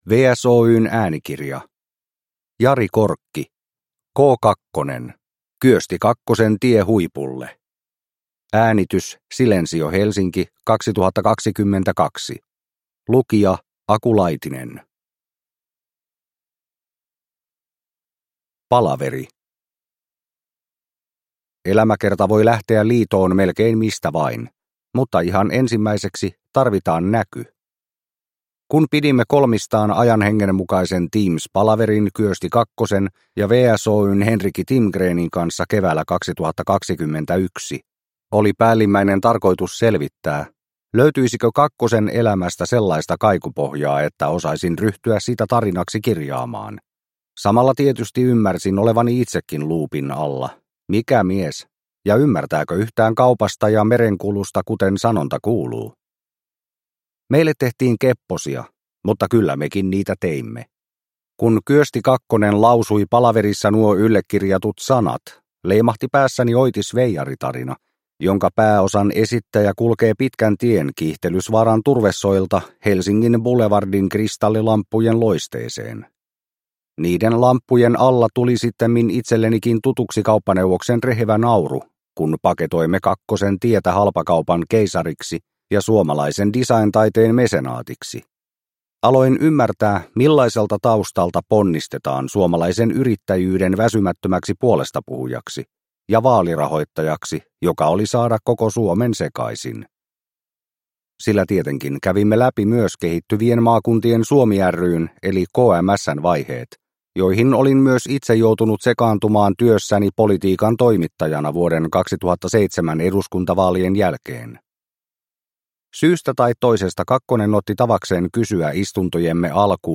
K2 - Kyösti Kakkosen tie huipulle – Ljudbok – Laddas ner